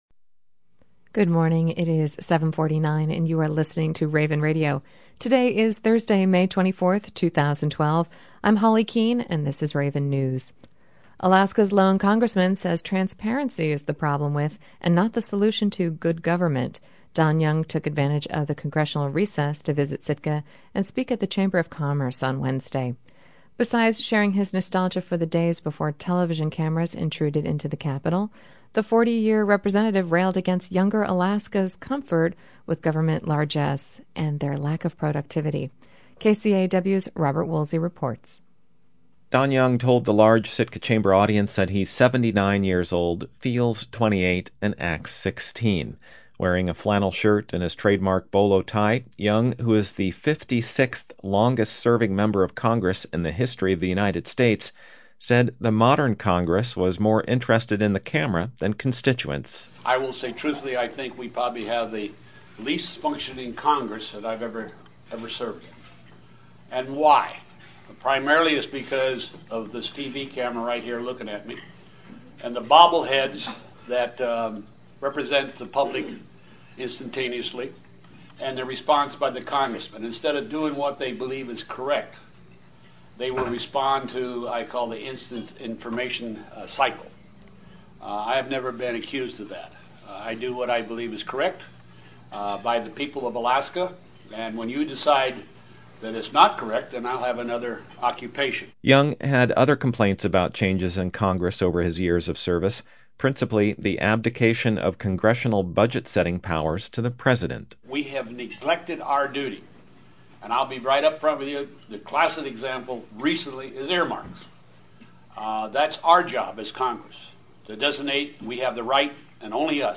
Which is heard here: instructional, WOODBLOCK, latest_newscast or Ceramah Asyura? latest_newscast